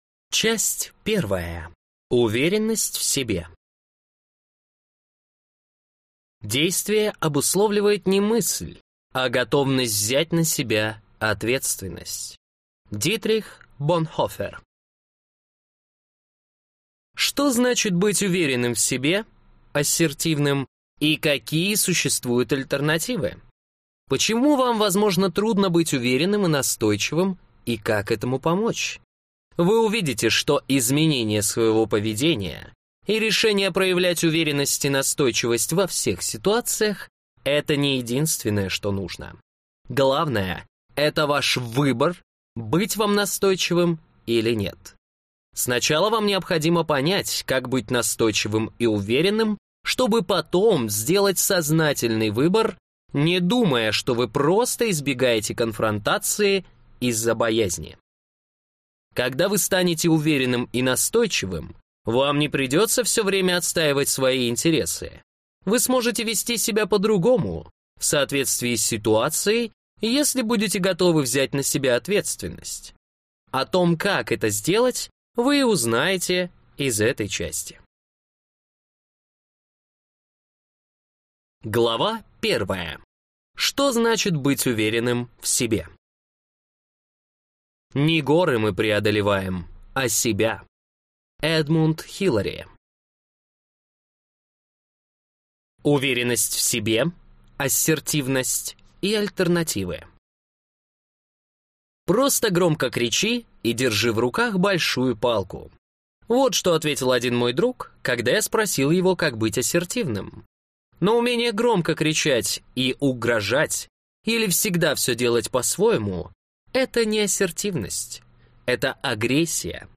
Аудиокнига Обрести уверенность в себе. Что означает быть ассертивным | Библиотека аудиокниг